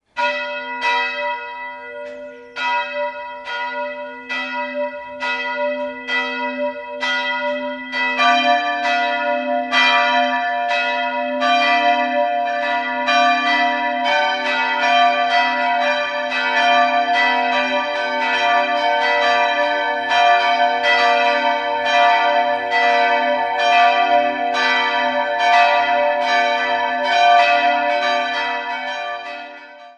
3-stimmiges Geläute: h'-d''-g'' Die kleine Glocke wurde um 1500 in Nürnberg gegossen, die mittlere Glocke 1962 von Friedrich Wilhelm Schilling und die große im Jahr 1647 von Leonhardt Löw in Nürnberg.